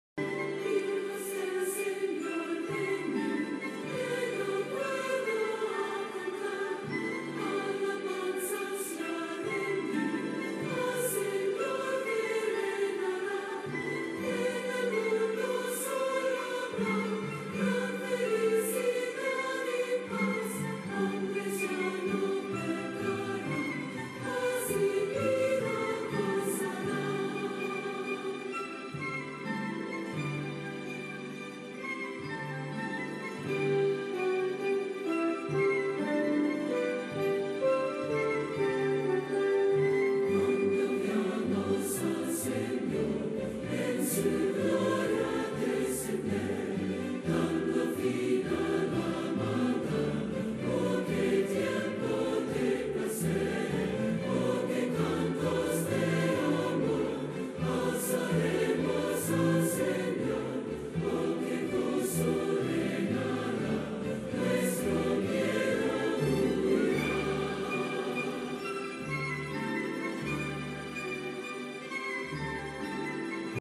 el Coro del Tabernáculo en Argentina marca el inicio de una velada histórica para conmemorar el centenario de la Iglesia de Jesucristo en Sudamérica.